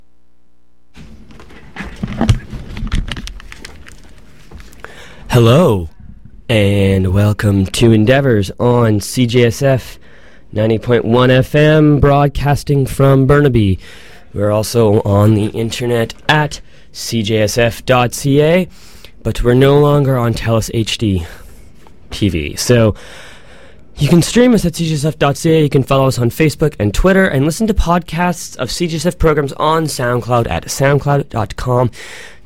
Description: Coverage and and interviews from the Vancouver Film Festival
Type: Interview